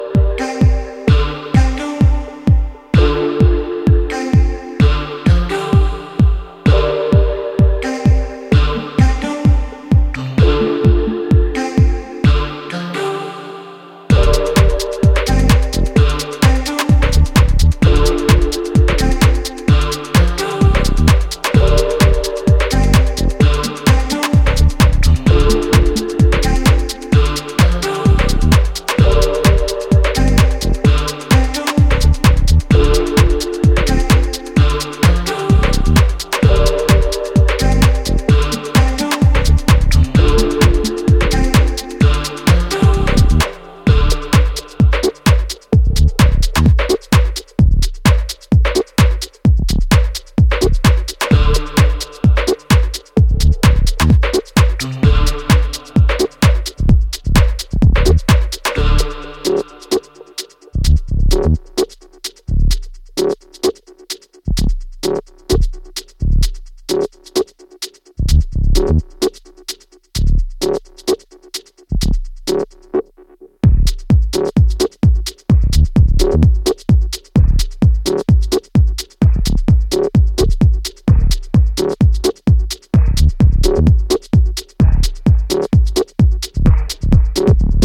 quirky, funky and gripping composition